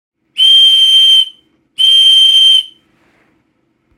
Silver whistle
It’s about 4cm long. This one has a plastic insert to make it whistle.
Whistle.mp3